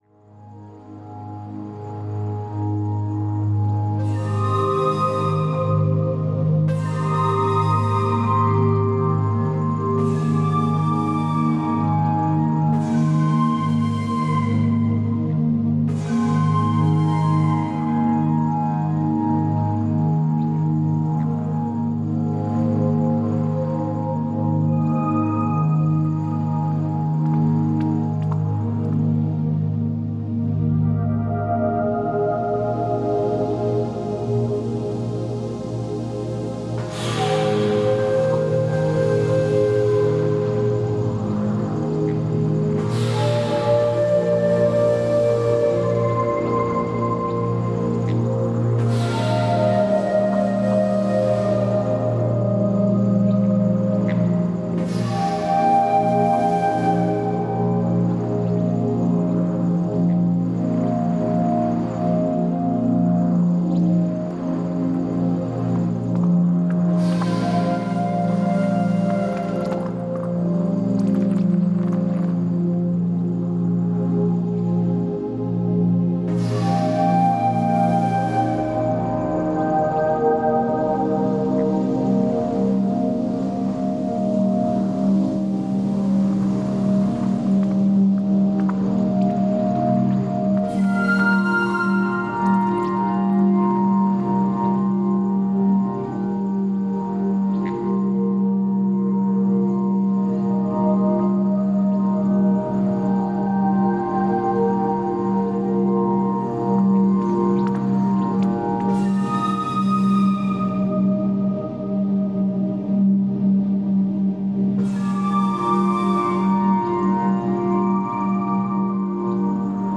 Музыка природы 0 466 Добавлено в плейлист